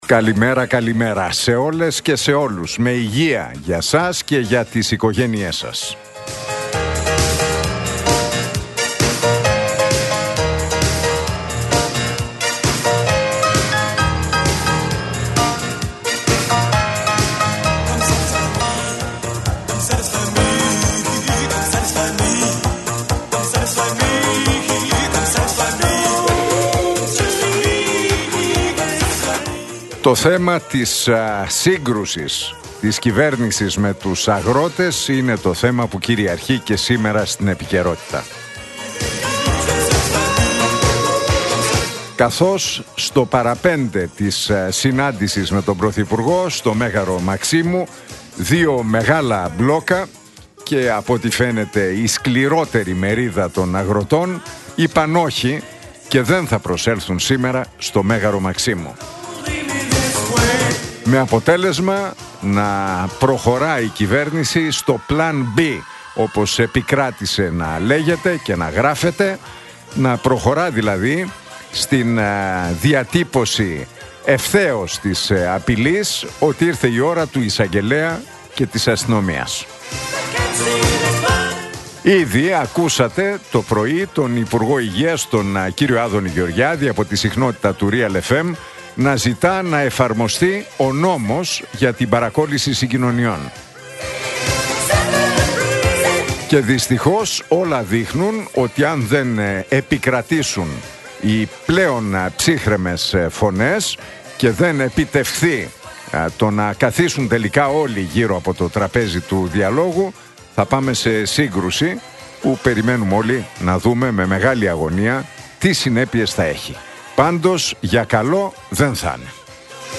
Ακούστε το σχόλιο του Νίκου Χατζηνικολάου στον ραδιοφωνικό σταθμό Realfm 97,8, την Τρίτη 13 Ιανουαρίου 2026.